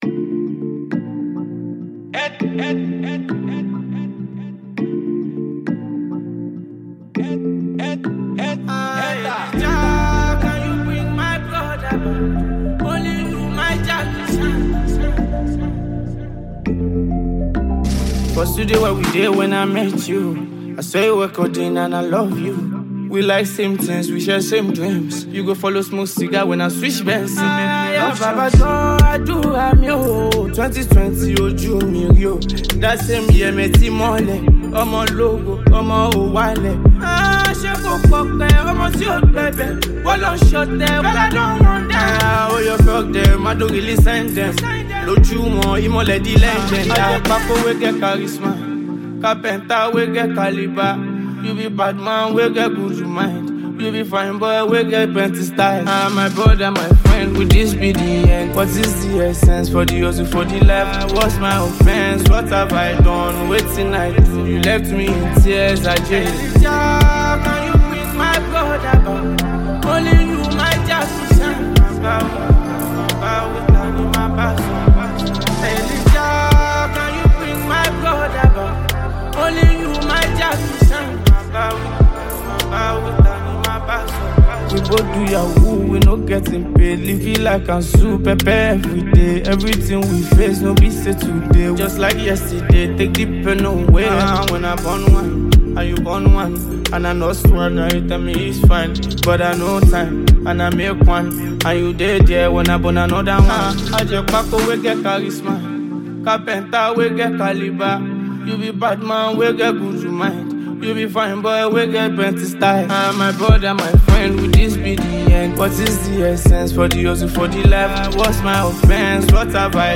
Nigerian singer-songwriter
With its infectious melody and heartfelt lyrics